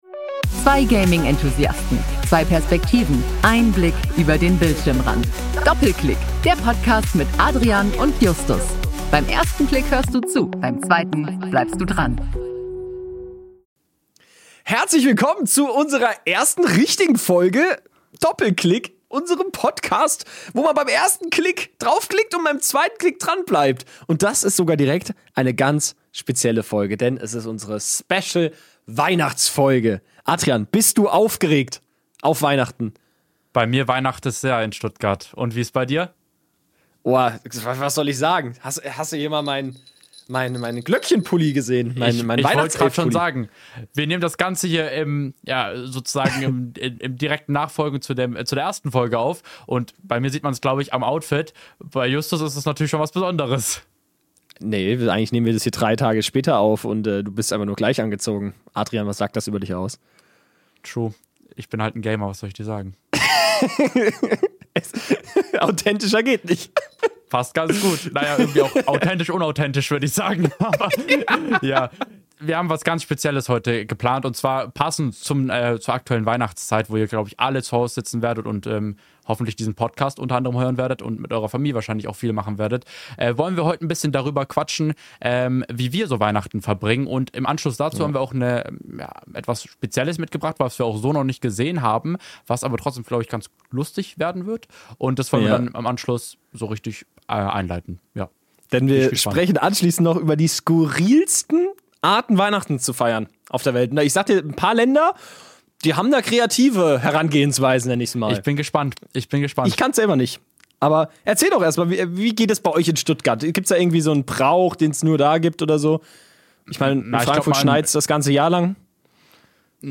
Von Schweden über Venezuela bis zu den Philippinen erfahrt ihr, wie unterschiedlich Weihnachten gefeiert wird. Dazu teilen die beiden Hosts ihre eigenen festlichen Rituale, erzählen Fun Facts und sorgen für jede Menge Weihnachtsstimmung.